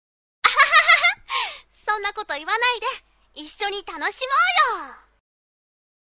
ヤエ-yae-　（♀）　14歳
陽気・元気・やる気のボジティブ人間。